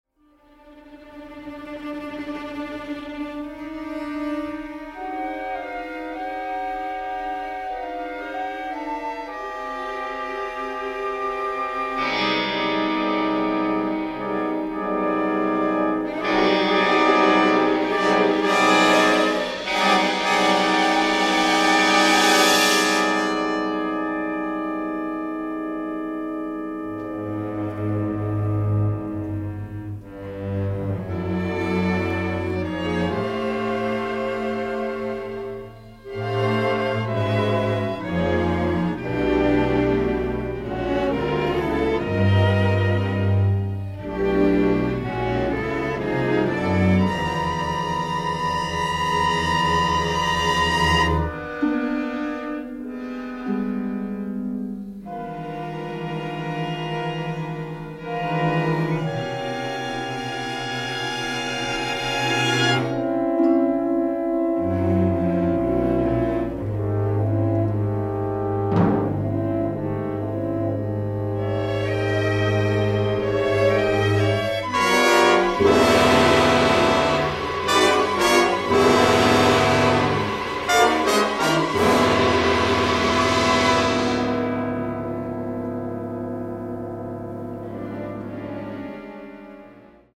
remarkable orchestral score